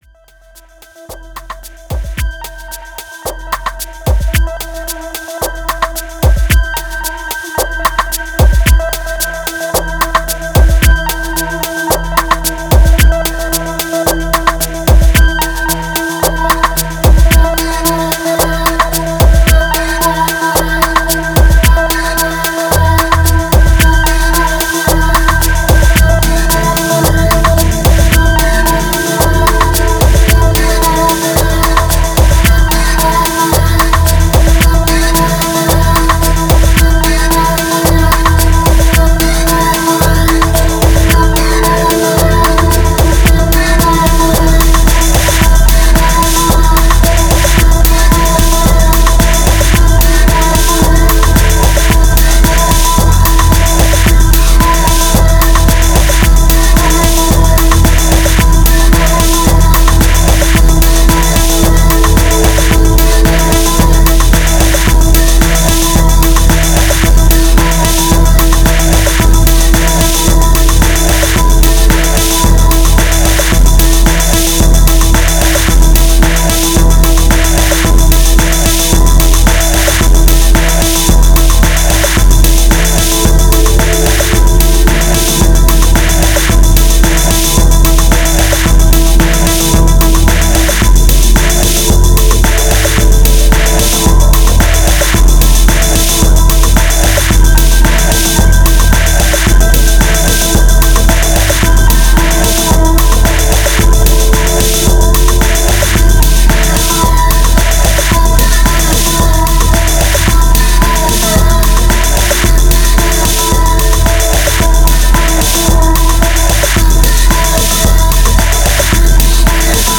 1028📈 - 92%🤔 - 111BPM🔊 - 2025-08-03📅 - 1442🌟
Dark Disco Sampler Distortion Ladder Moods Monolith Doom